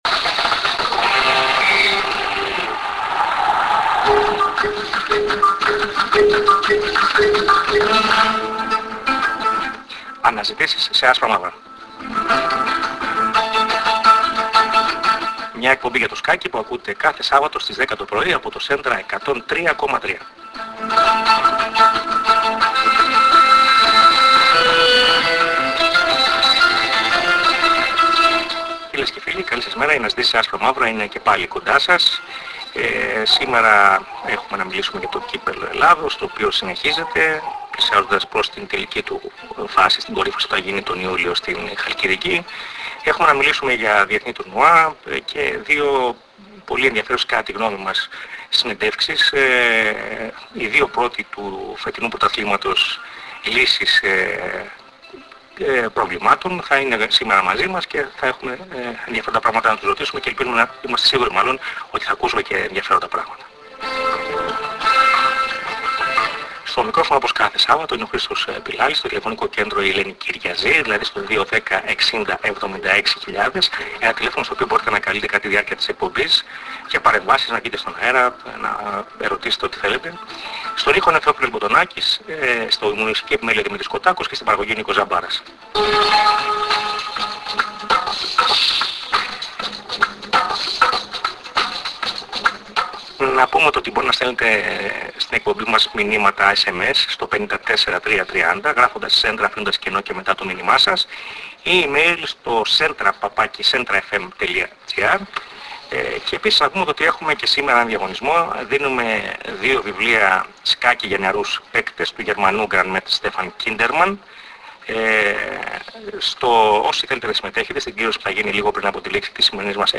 Η εκπομπή είναι ανοιχτή σε παρεμβάσεις των ακροατών.